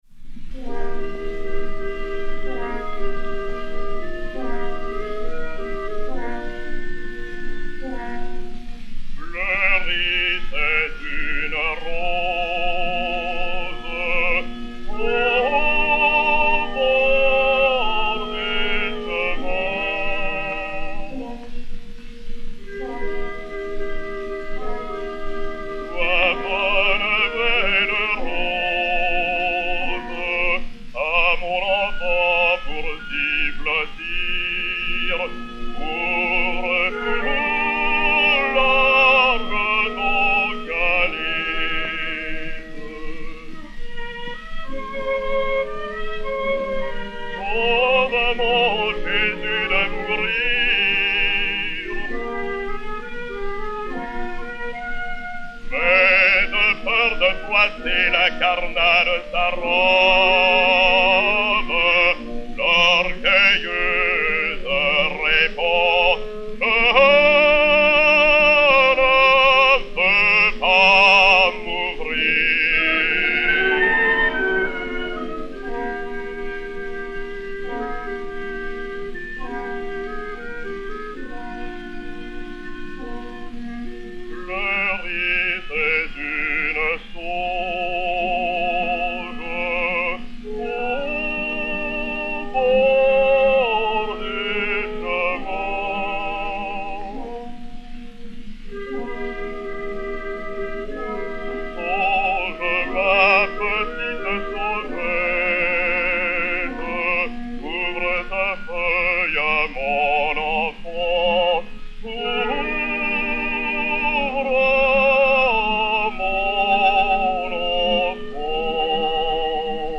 et Orchestre